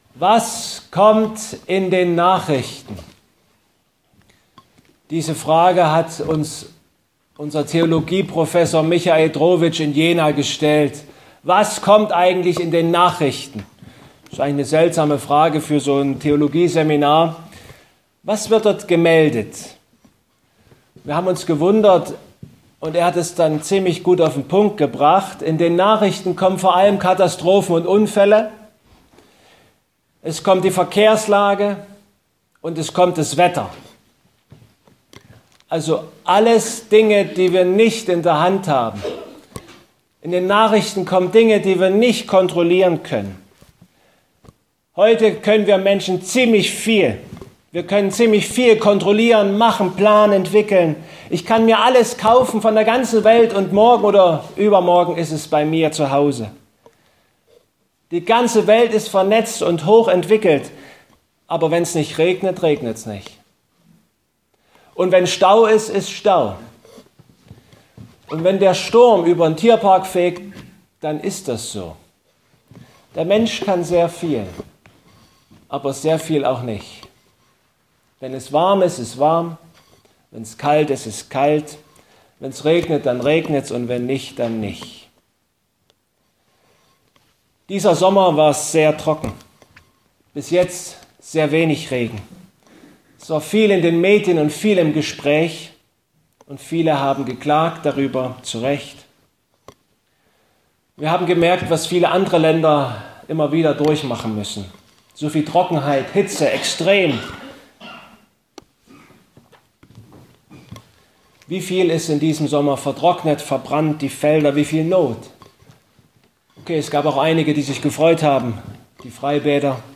Jesaja 55 Gottesdienstart: Erntedankgottesdienst Es ist unüberhörbar und unübersehbar